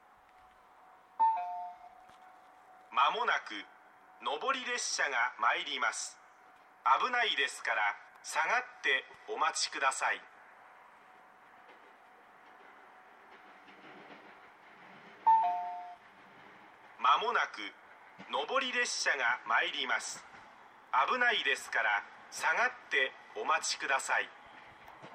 ２番線羽越本線
接近放送普通　新屋行き接近放送です。